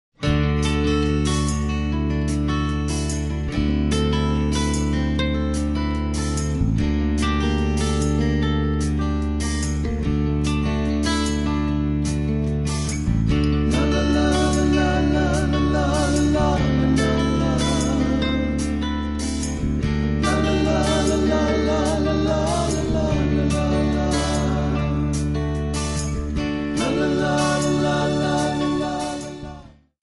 Backing track files: 1970s (954)